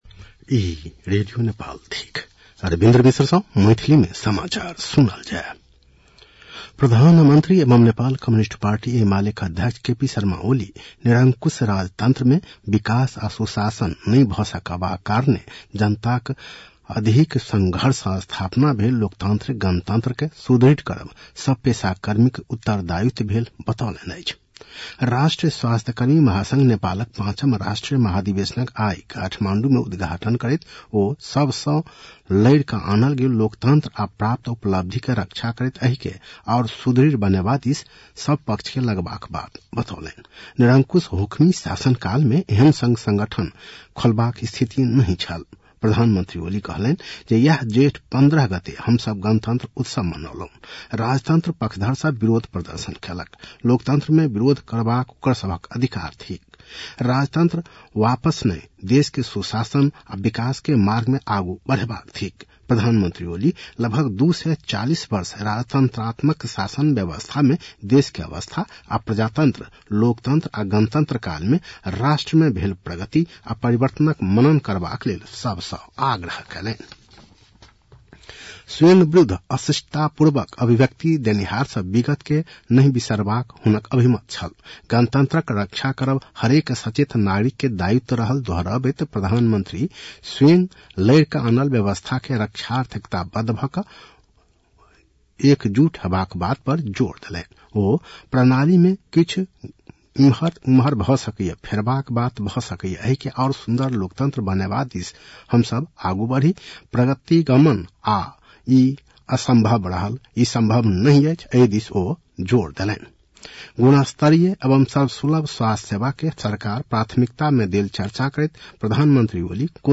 मैथिली भाषामा समाचार : १७ जेठ , २०८२
6-pm-Maithali-news-.mp3